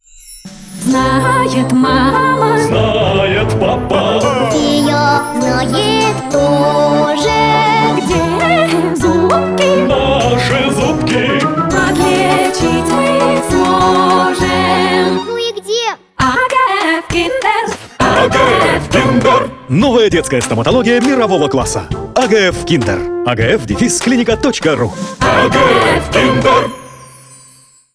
Зацените рекламную песенку
Нужно темп быстрее раза в полтора минимум По форме подсократить. Например, за счет не очень логичных азиатских вкраплений в "припеве" И взрослые голоса - на передний план, на уровень детского голоса, а то разборчивость текста страдает.